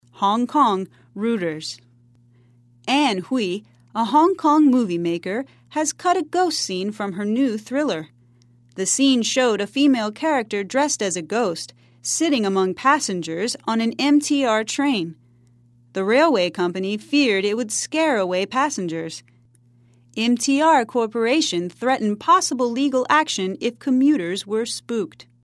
在线英语听力室赖世雄英语新闻听力通 第32期:恐怖片遭剪片的听力文件下载,本栏目网络全球各类趣味新闻，并为大家提供原声朗读与对应双语字幕，篇幅虽然精短，词汇量却足够丰富，是各层次英语学习者学习实用听力、口语的精品资源。